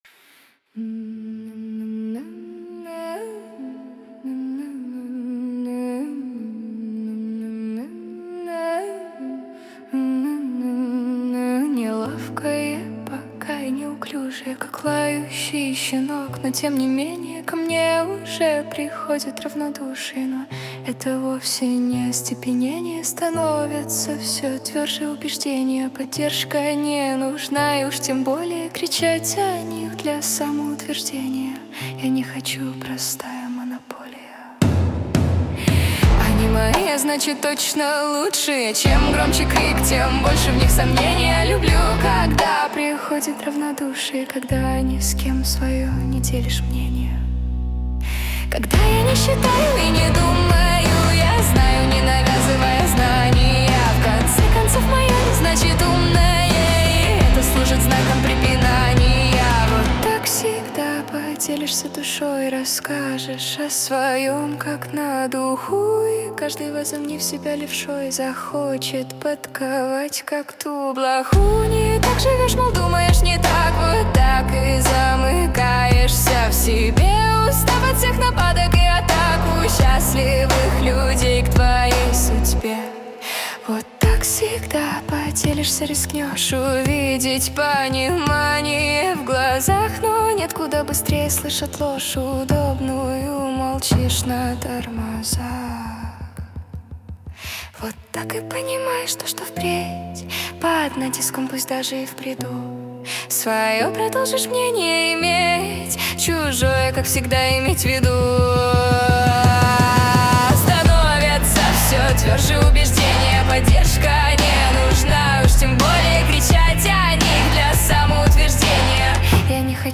Качество: 320 kbps, stereo
Стихи, Нейросеть Песни 2025